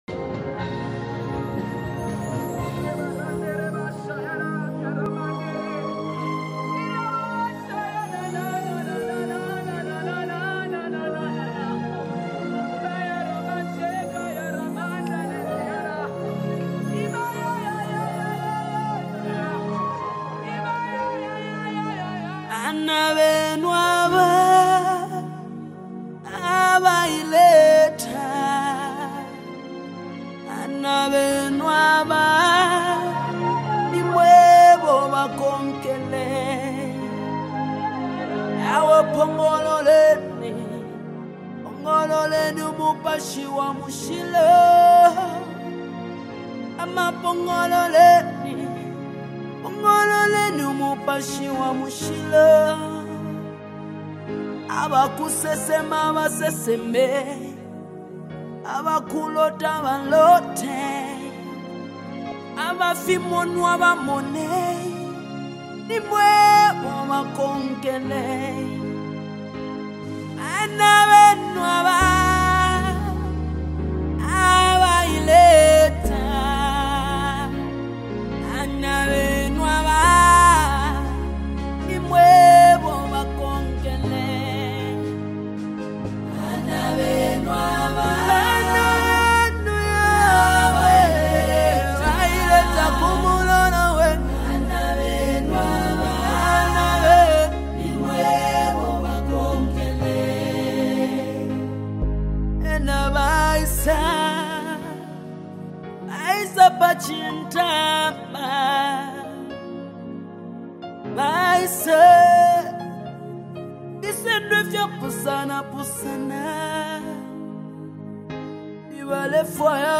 📅 Category: Zambian Deep Worship Song